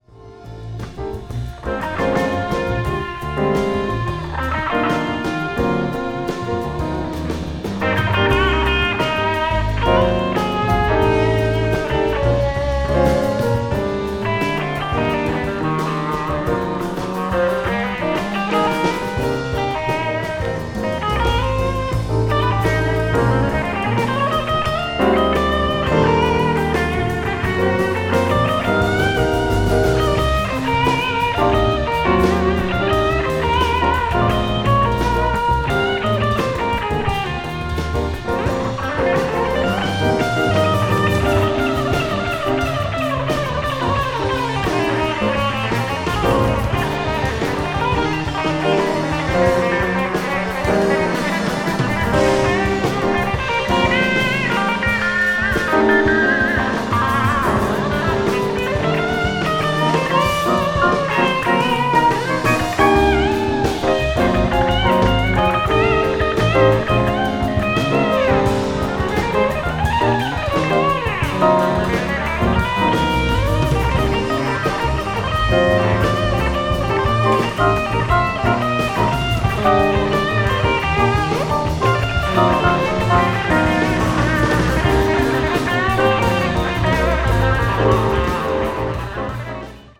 contemporary jazz